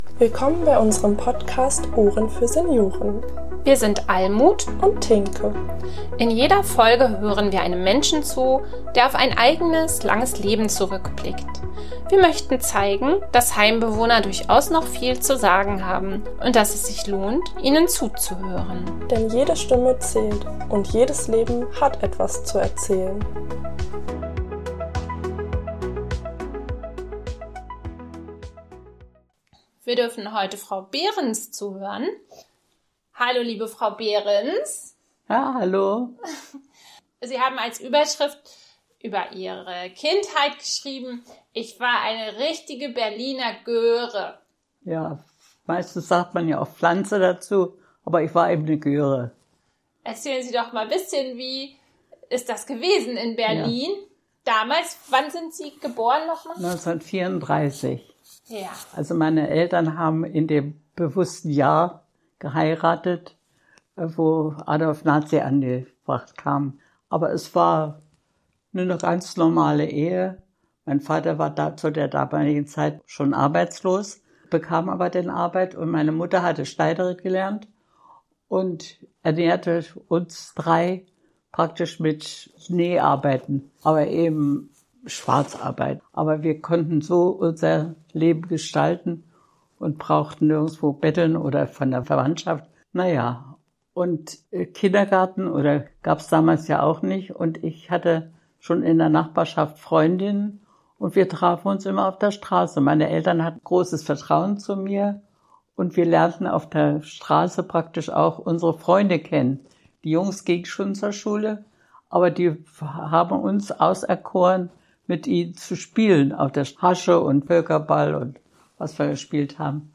PS: Im Hintergrund hört Ihr in den Folgen immer mal typische Heimgeräusche- den Essenwagen, die Klingelanlage, Hausmeisterarbeiten... usw. Das gehört dazu und lässt sich nicht ausblenden.